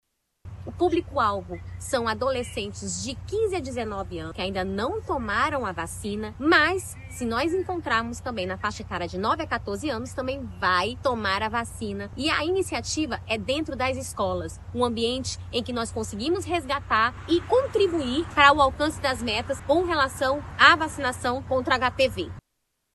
A diretora-presidente da Fundação de Vigilância em Saúde (FVS-RCP), Tatyana Amorim, enfatiza a importância da atualização da caderneta de vacinação dos estudantes.